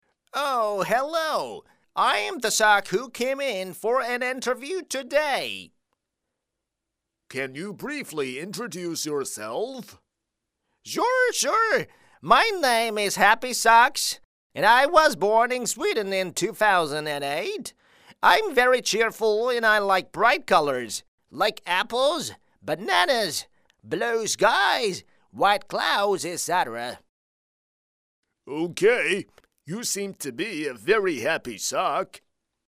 【角色】美式 儿童书 奇怪的感觉
【角色】美式 儿童书 奇怪的感觉.mp3